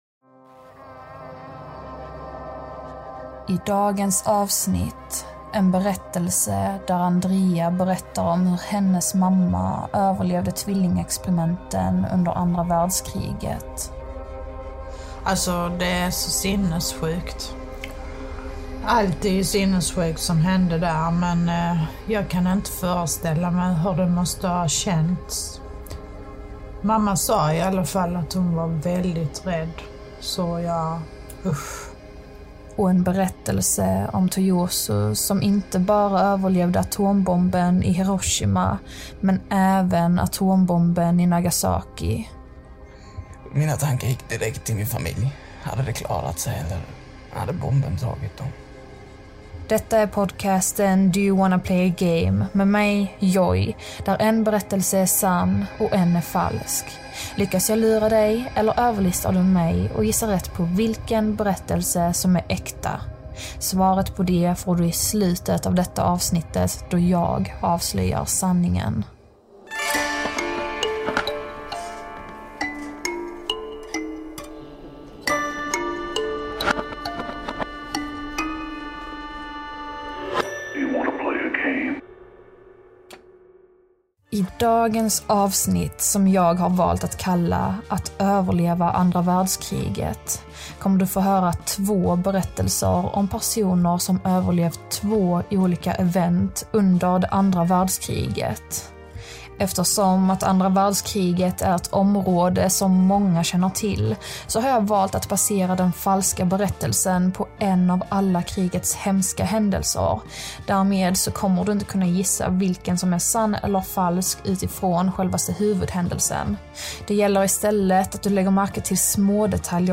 I dagens avsnitt får du höra två olika berättelser om personer som överlevt andra världskriget. En handlar om tvillingexperimenten i en av Auschwitz koncentrationsläger och en handlar om atombomberna som släpptes i Hiroshima och Nagasaki. En av berättelserna är sann och en av dem är falsk.